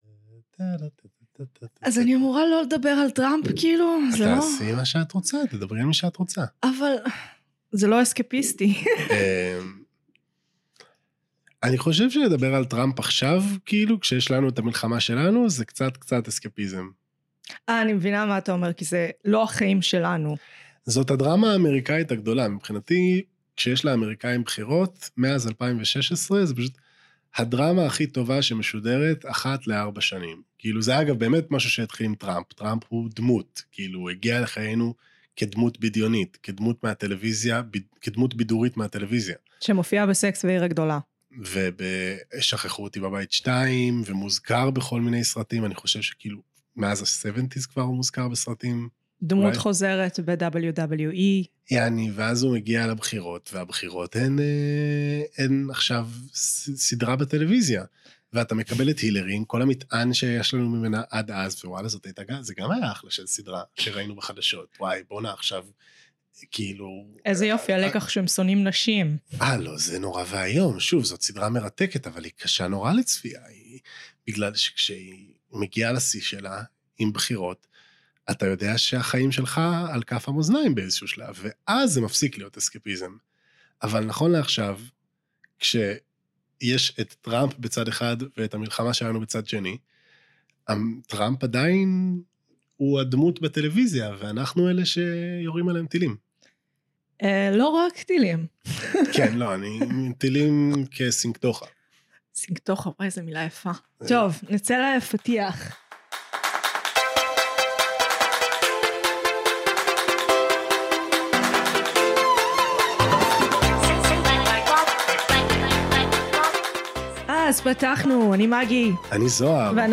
הצטרפו אלינו לשיחה לא מסוננת וכמעט לא ערוכה.